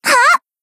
BA_V_Hanako_Swimsuit_Battle_Shout_3.ogg